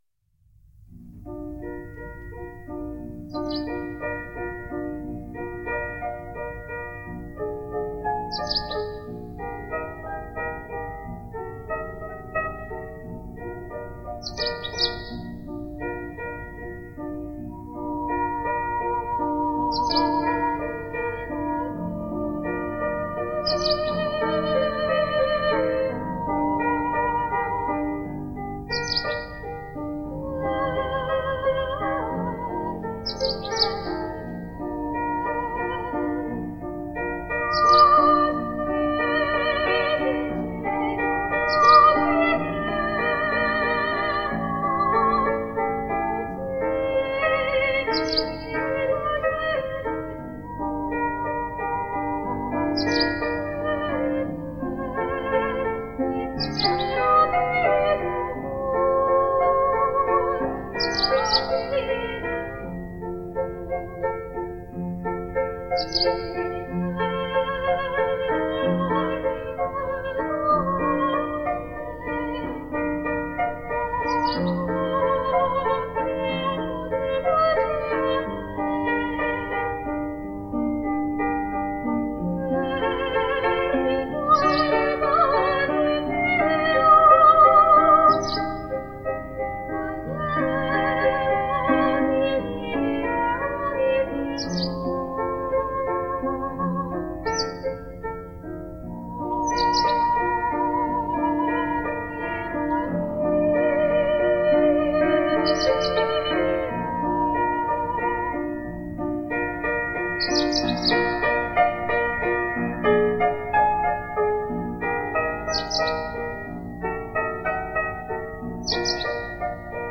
I am a soprano.
(Listen out for the green singing finch in the back (and often fore) ground. He used to sit beside the piano and join in!)
Please accept my apologies for the often inadequate piano playing, and the unprofessional quality of recording.